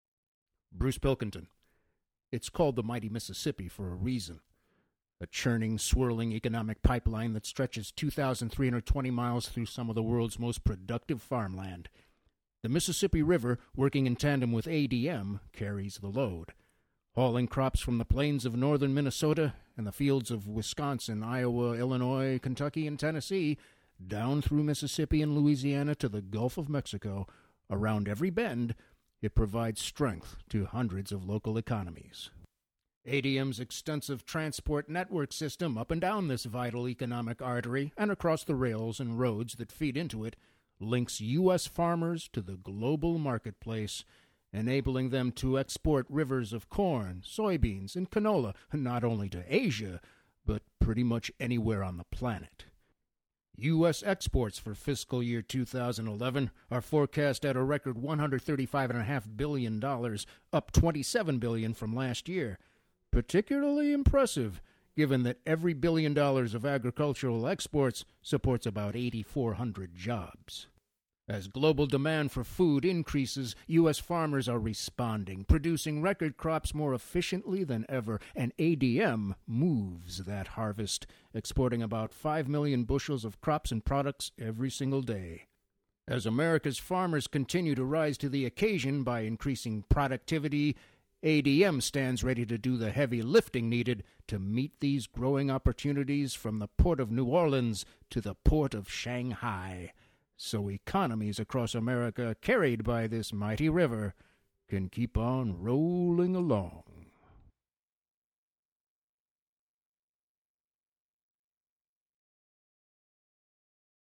INSTINCTIVE CHOICES CHARACTER VOICES commercials,industrials: versatile, here to please the client! From real-person read to zany character voice
Sprechprobe: Industrie (Muttersprache):
Mid-range, can do serious PSA, warm real-person, or zany character!